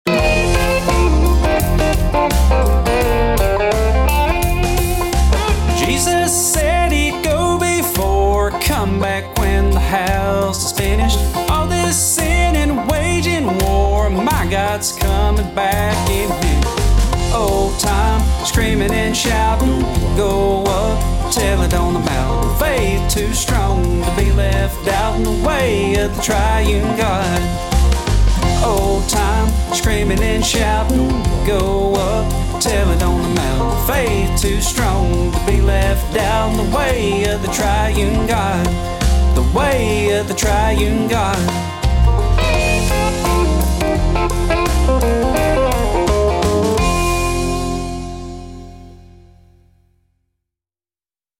I'm playing all instruments and singing all four parts.
bluegrass